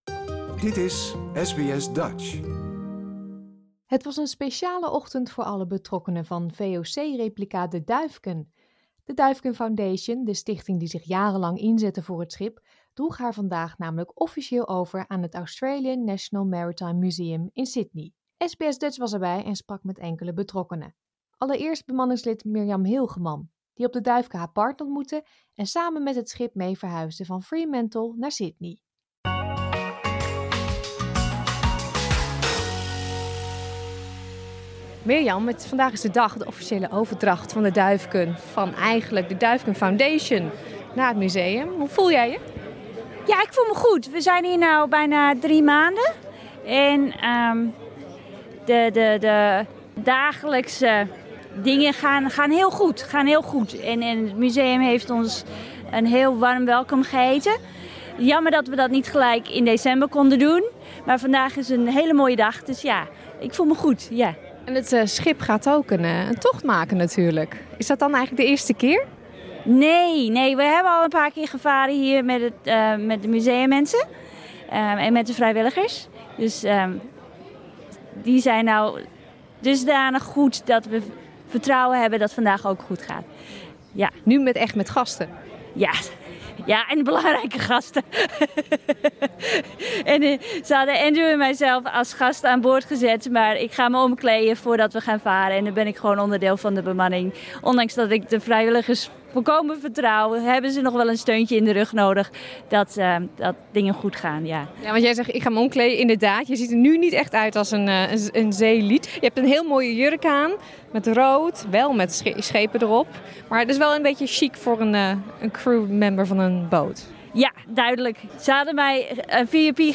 Het zeilschip werd vandaag officieel overgedragen aan het Australian National Maritime Museum in Sydney. SBS Dutch was erbij en sprak met enkele betrokkenen.